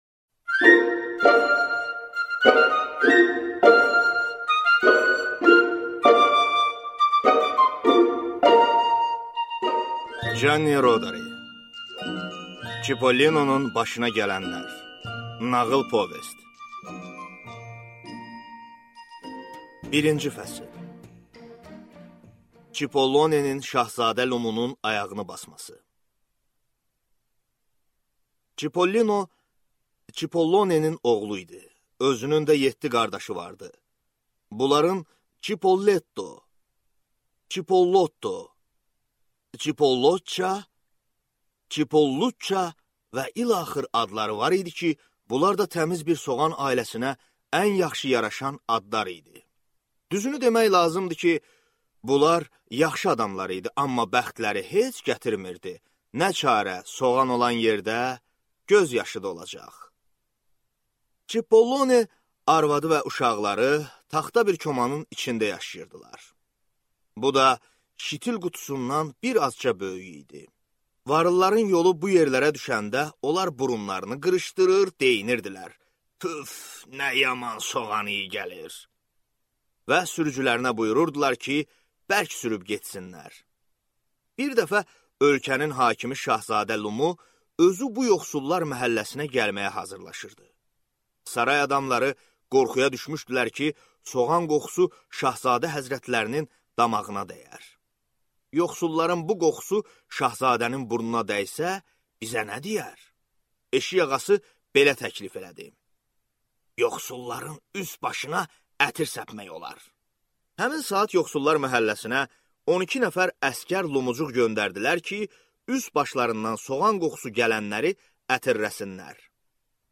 Аудиокнига Çipollinonun macəraları | Библиотека аудиокниг